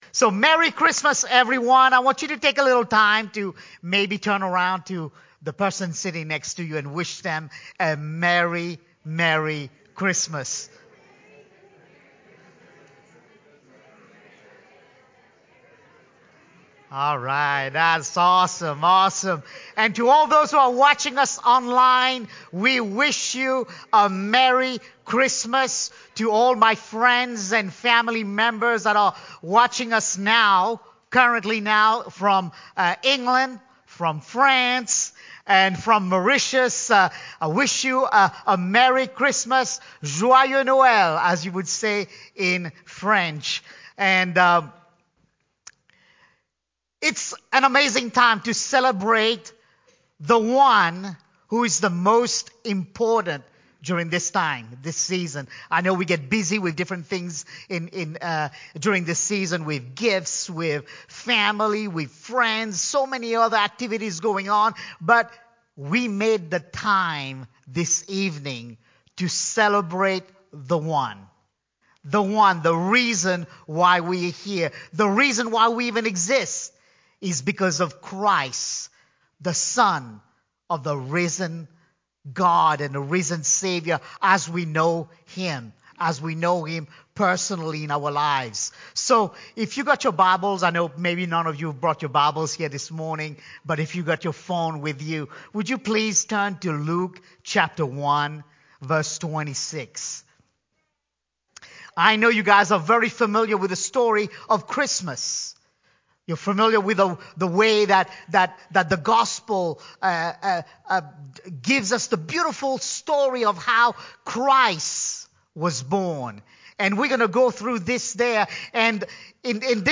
Christmas Service 2022